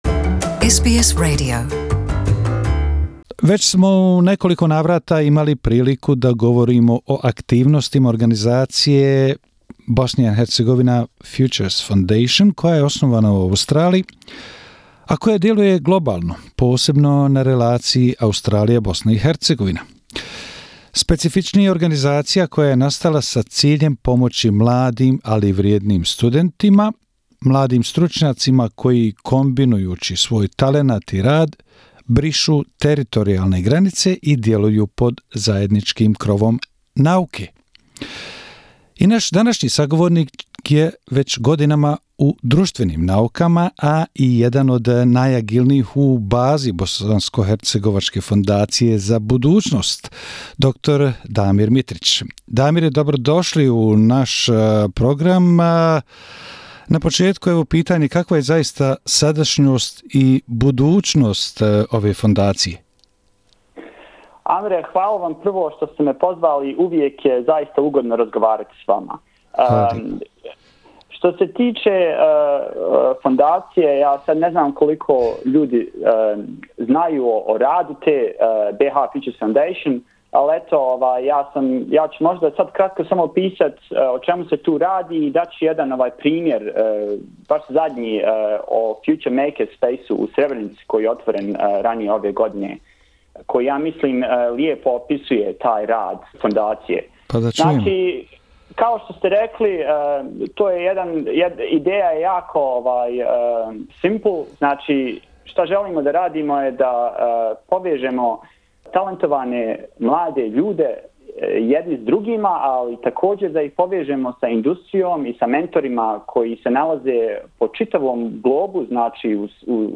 Gost u našem radio programu